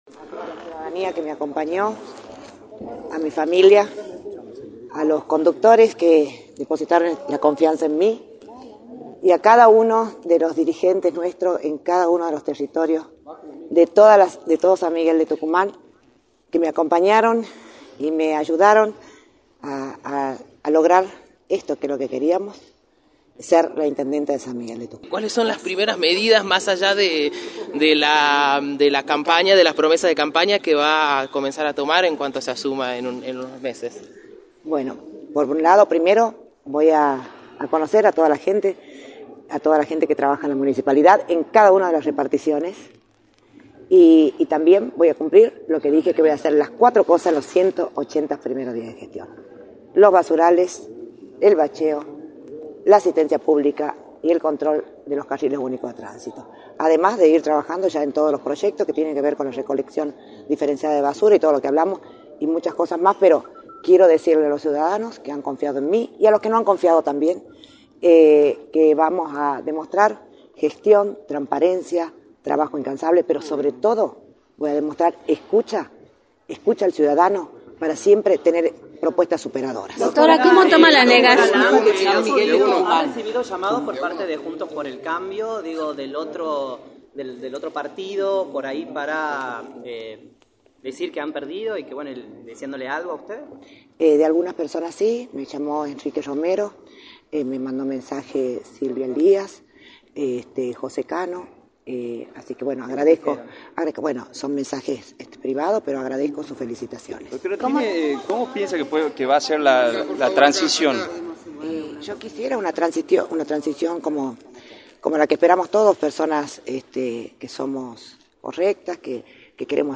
“Voy a cumplir con las 4 cosas que prometí en los primeros 180 días, los basurales, el bacheo, la asistencia pública y el control de los carriles únicos de tránsito, quiero decirle a los ciudadanos que vamos a mostrar gestión, transparencia, trabajo incansable y sobre todo, escucha a los ciudadanos para tener siempre propuestas superados” remarcó la Intendente electoral, la Dra. Rossana Chahla, en entrevista para Radio del Plata Tucumán, por la 93.9.